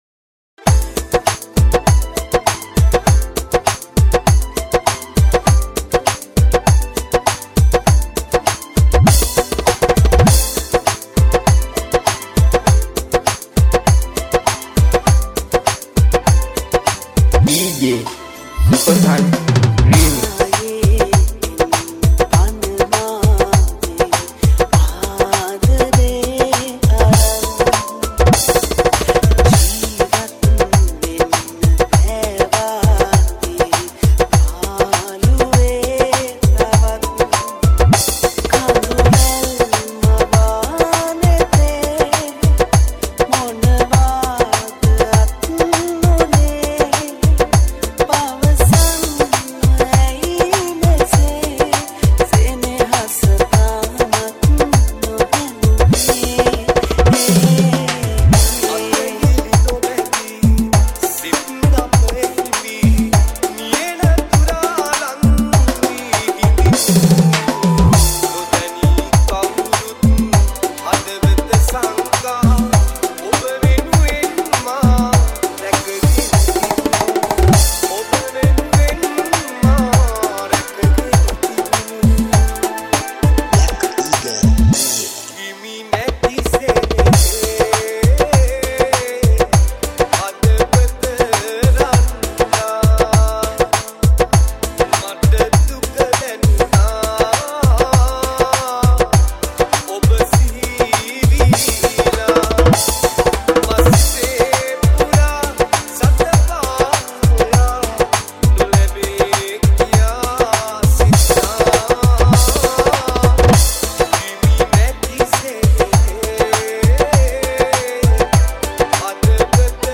Category: Dj Remix